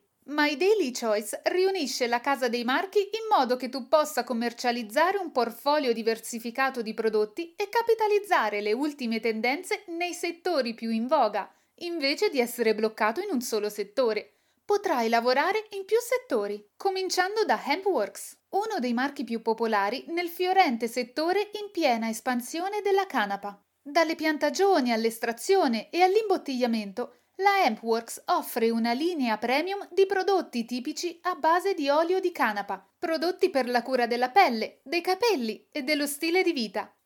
促销广告